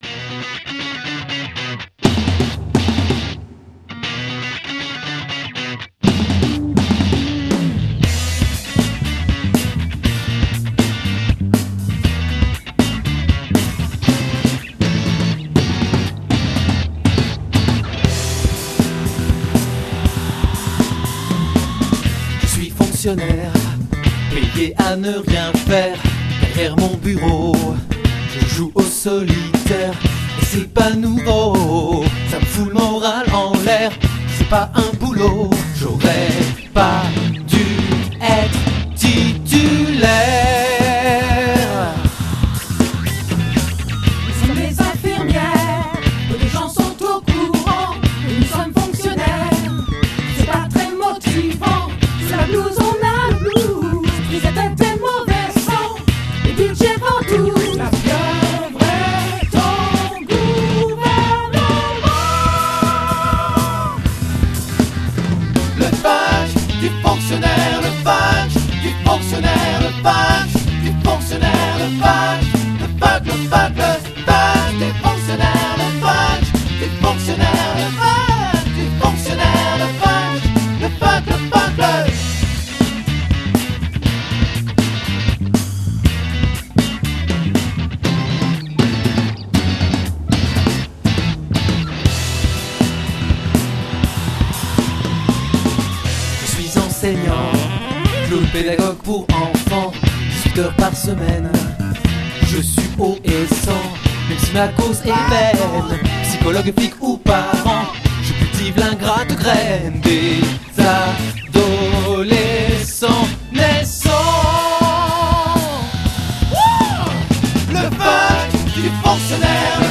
trio rock-funk-mélodique
power trio
Voix, guitares, claviers
Batterie, choeurs
Basse, choeurs